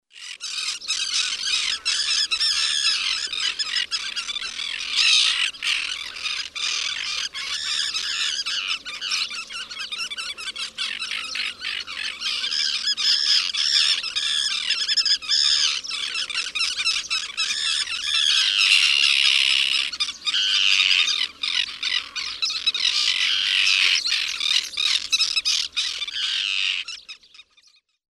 gaviotas.mp3